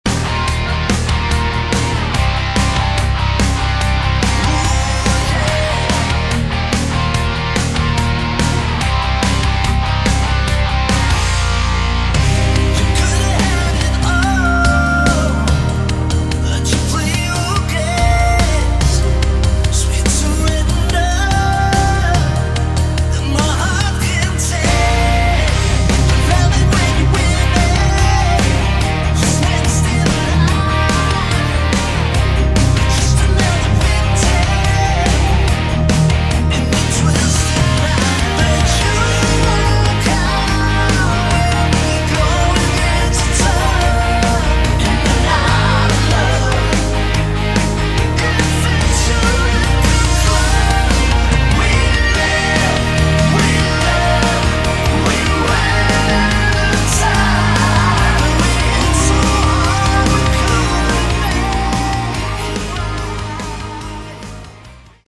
Category: Hard Rock
Vocals, Bass
Guitar
Drums